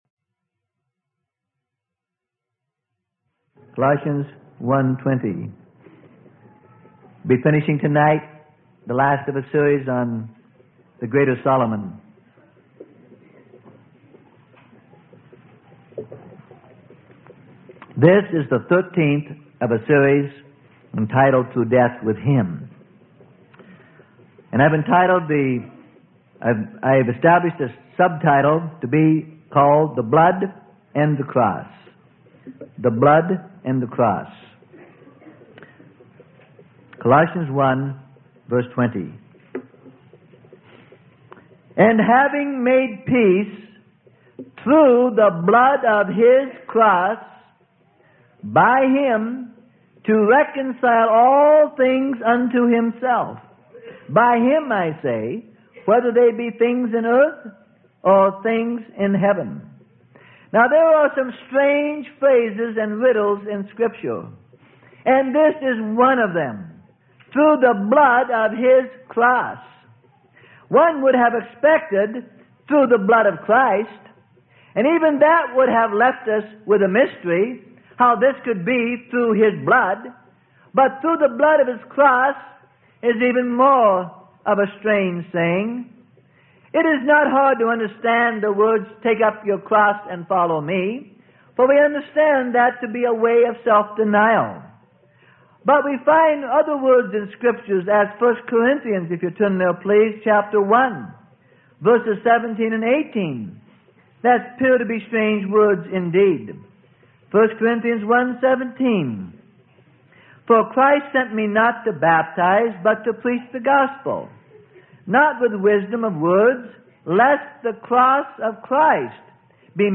Sermon: Through Death With Him - Part 13: The Blood and the Cross - Freely Given Online Library